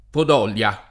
vai all'elenco alfabetico delle voci ingrandisci il carattere 100% rimpicciolisci il carattere stampa invia tramite posta elettronica codividi su Facebook Podolia [ pod 0 l L a ] (russo Podol’ja [ pad 0 l’ L ë ]; pol.